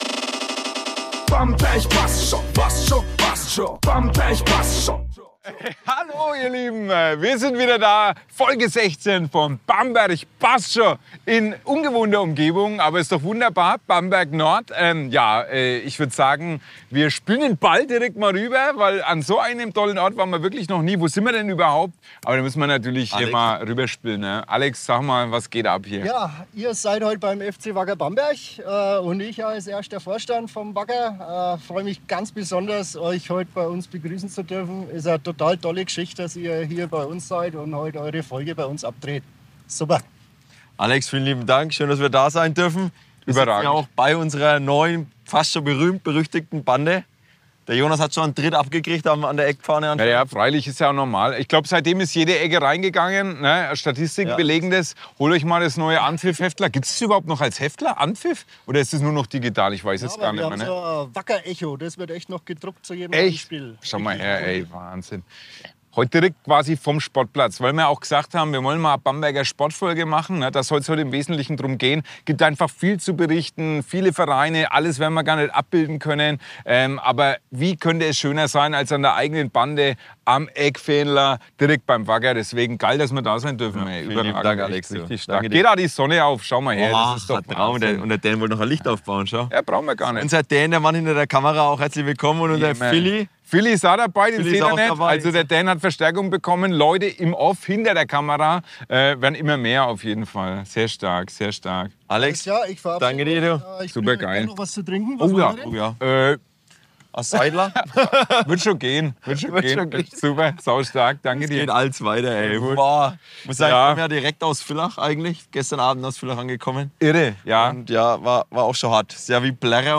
Beschreibung vor 7 Monaten Folge 16 – direkt vom Spielfeldrand beim FC Wacker Bamberg! Diesmal dreht sich alles um den Sport in Bamberg – mit Geschichten, Erinnerungen und Anekdoten zwischen Eckfahne, Basketballhalle und BMX-Spot.
Aufgenommen mit Seidler-Bier in der Hand, Sonne im Gesicht und Baustellenlärm im Hintergrund – authentischer wird’s nicht.